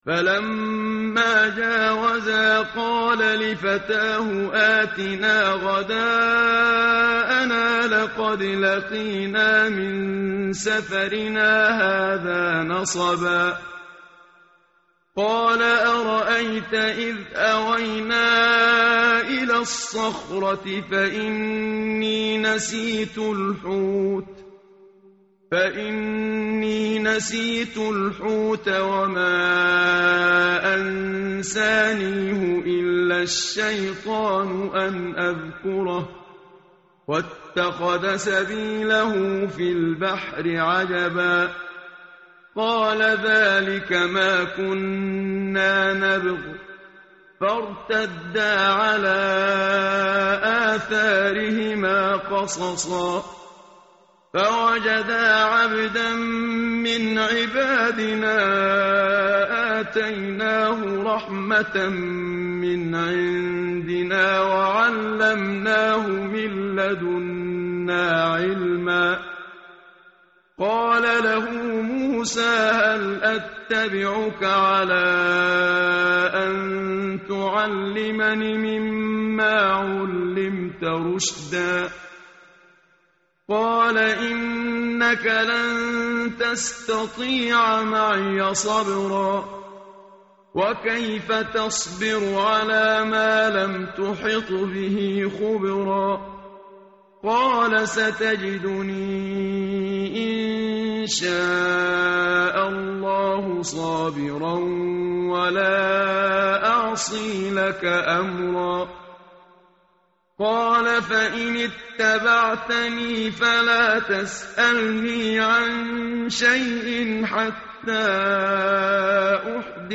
tartil_menshavi_page_301.mp3